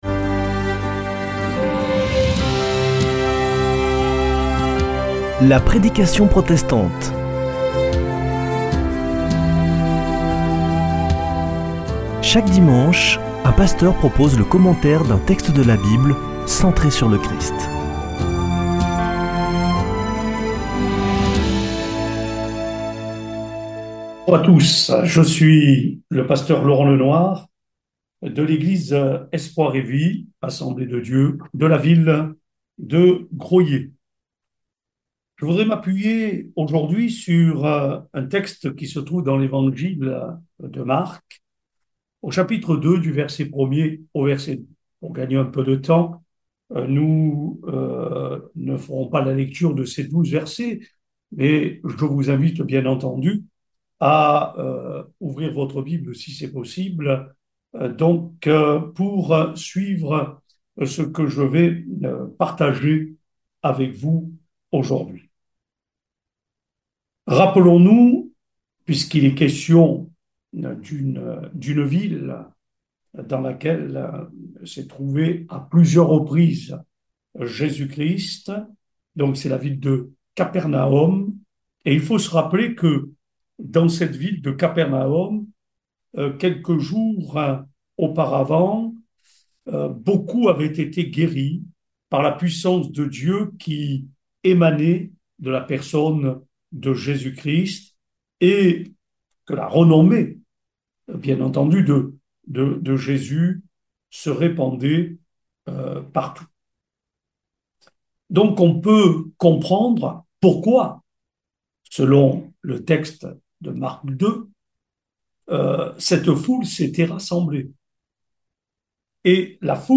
Accueil \ Emissions \ Foi \ Formation \ La prédication protestante \ Où est ton trésor ?
Une émission présentée par Des protestants de la région